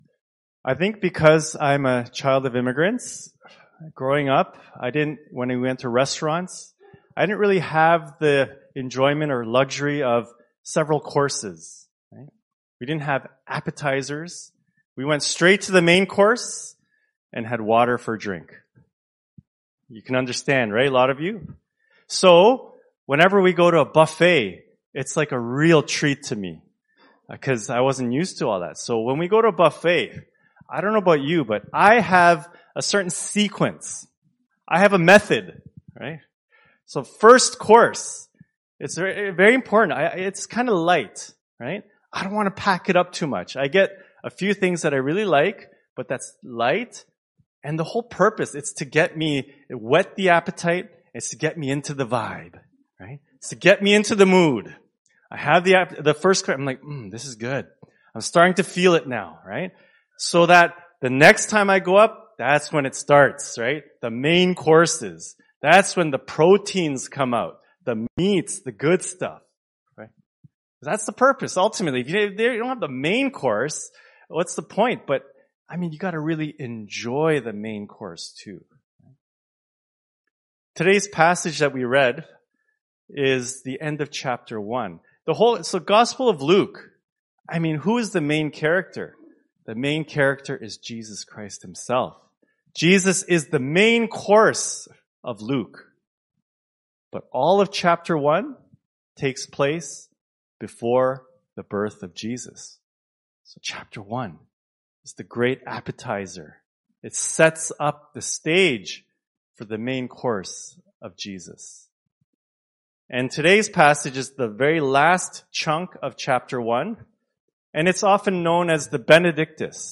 Scripture Passage Luke 1:68-79 Worship Video Worship Audio Sermon Script INTRODUCTION I enjoy buffets.